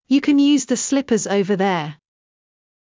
ﾕｳ ｷｬﾝ ﾕｰｽﾞ ｻﾞ ｽﾘｯﾊﾟｰｽﾞ ｵｰﾊﾞｰ ｾﾞｱ